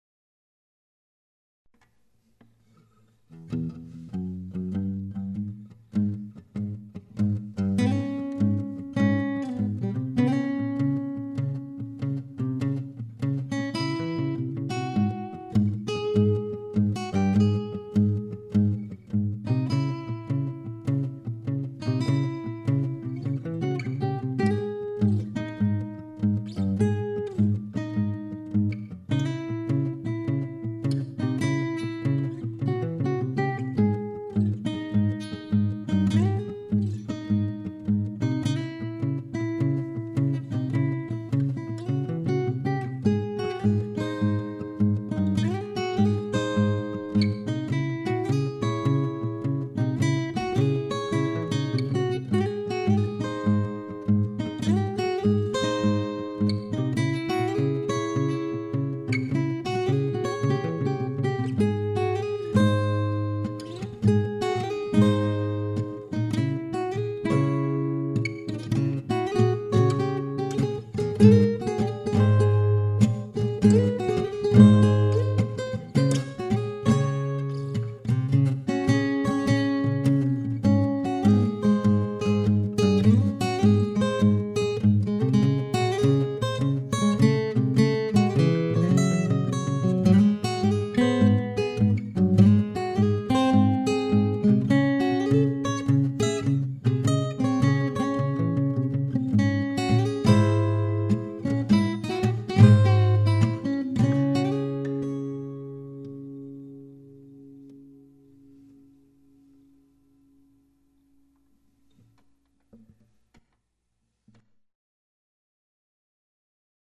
An instrumental